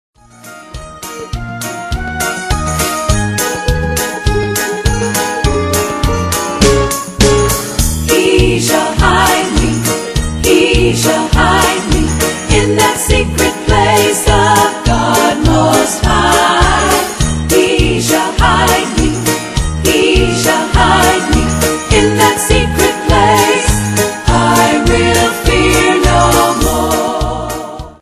Song Clip